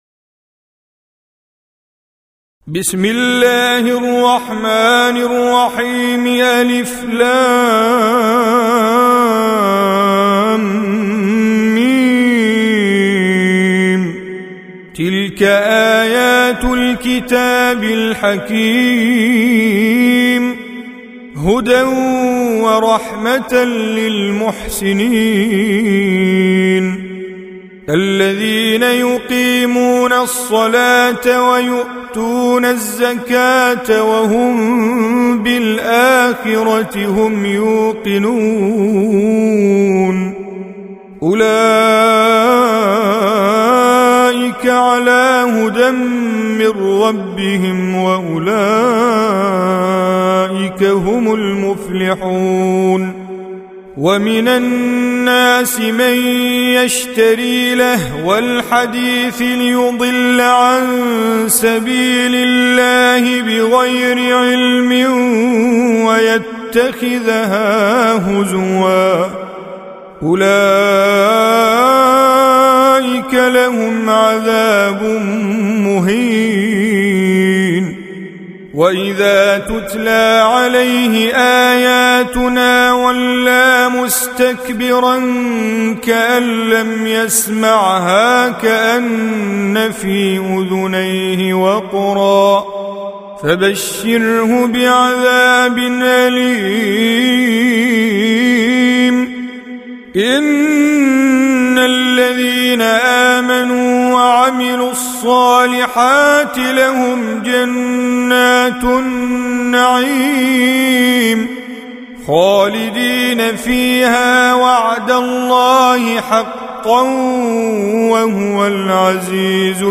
31. Surah Luqm�n سورة لقمان Audio Quran Tajweed Recitation
Surah Repeating تكرار السورة Download Surah حمّل السورة Reciting Mujawwadah Audio for 31.